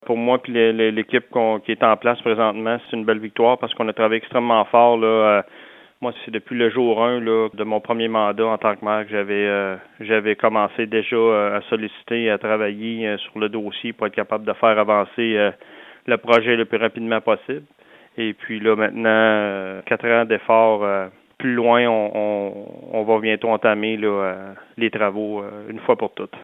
Le maire Dupont a bien hâte de voir sa municipalité retirée de la liste peu enviable des 81 municipalités qui n’ont pas leurs stations de traitement des eaux usées et qui rejettent encore leurs eaux usées dans l’environnement :